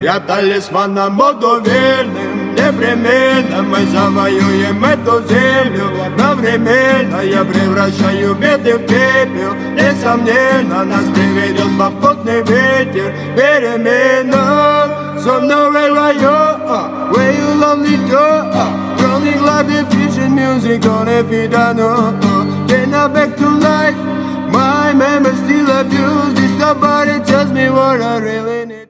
Рэп и Хип Хоп
кавер